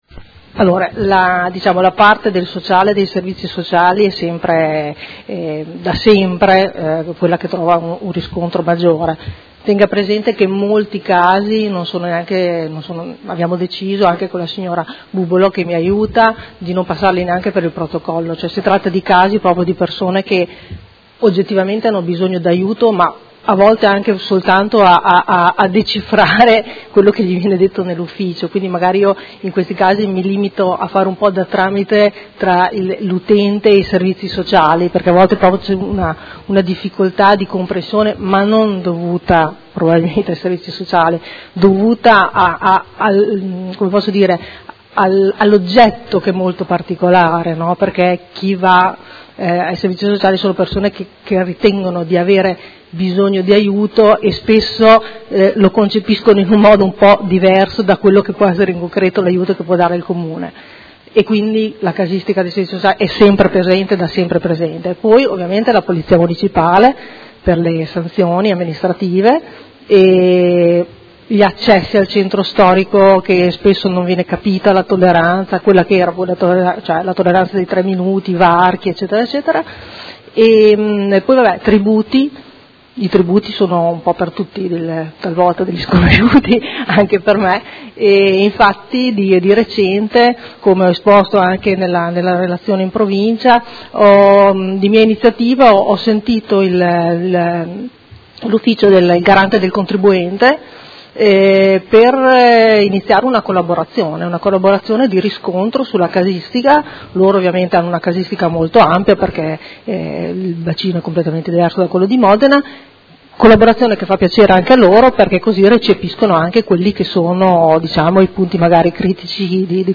Seduta del 28/03/2019. Dibattito su relazione del Difensore Civico - Avv. Patrizia Roli - sull'attività svolta nell'anno 2018